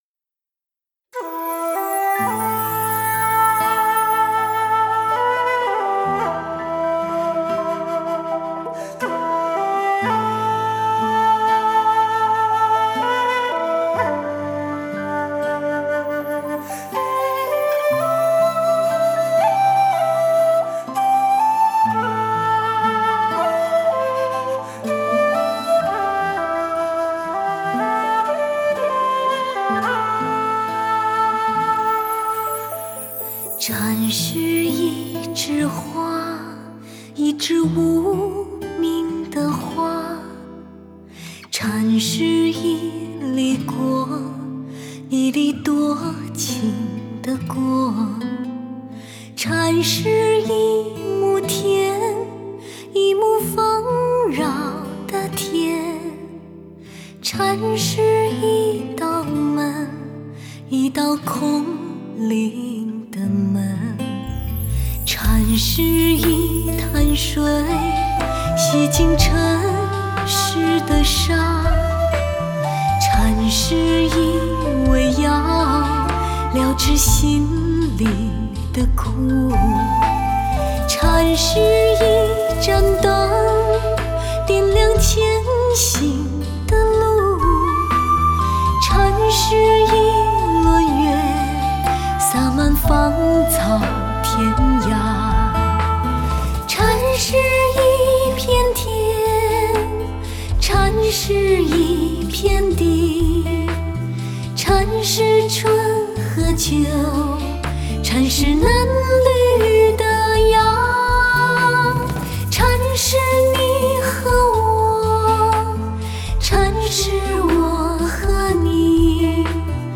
Жанр: Modern Traditional / Chinese pop